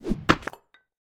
axe.ogg